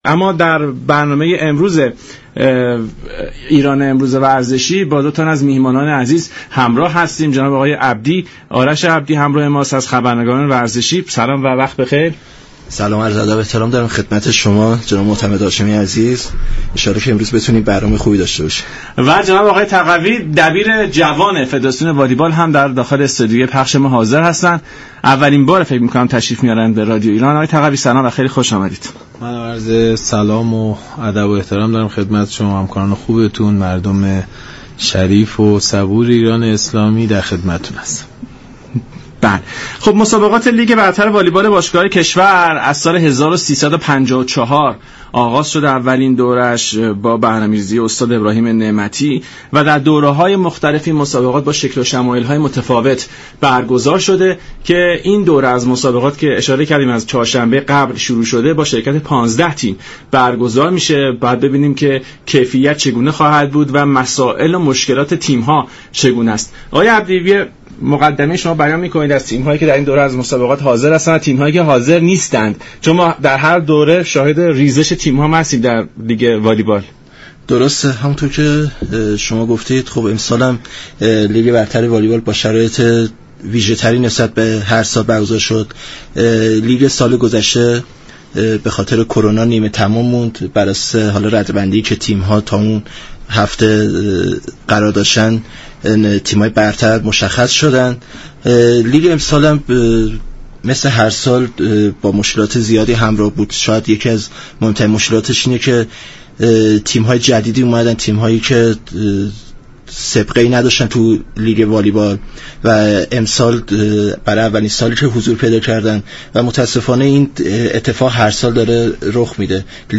گفت و گوی رادیویی
برنامه ایران امروز شنبه تا سه شنبه هر هفته ساعت 11:45 از رادیو ایران پخش می شود.